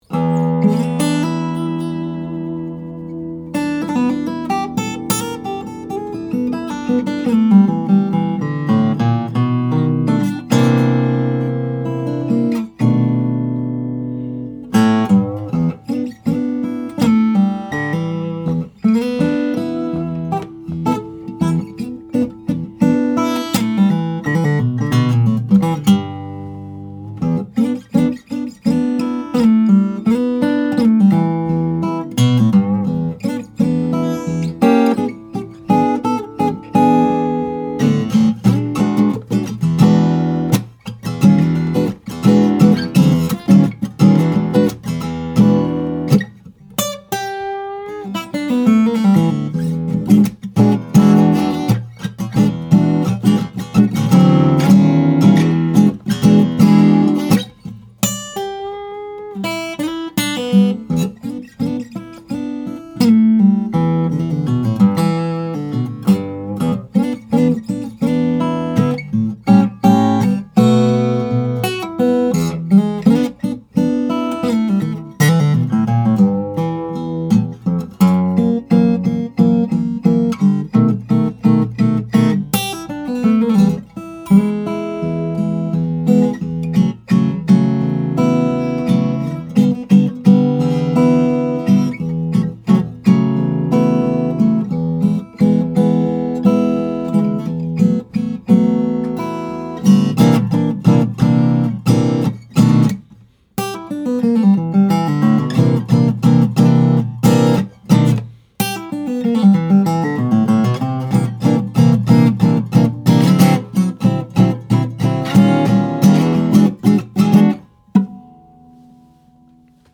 This 2009 Charis SJ is the one for you, if what you’re looking for includes the following: note separation, powerful bass, expressive mids under bell-like trebles, player ergonomics, as well as premium tonewoods.